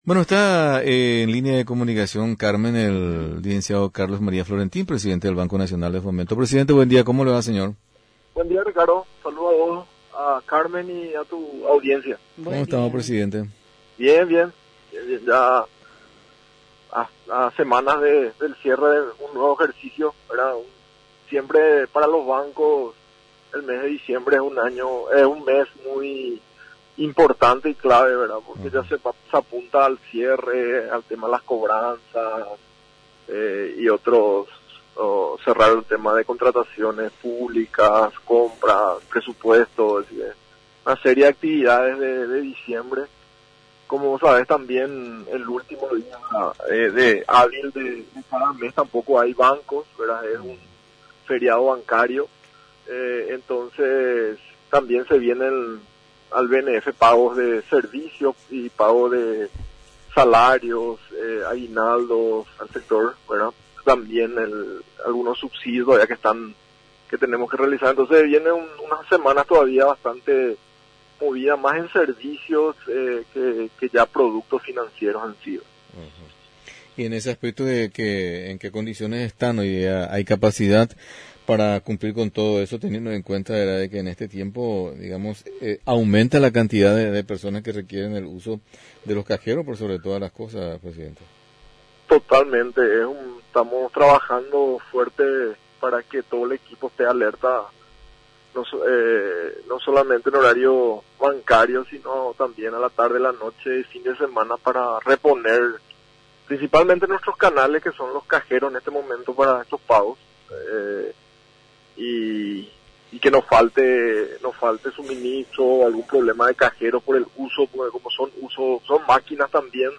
Ahora ya se entra también a otro segmento de la línea crediticia, y que como banco estatal está obligado a apoyar, en otro ámbito social, más allá de lo exclusivamente relacionado a la situación del covid-19, argumentó el entrevistado. https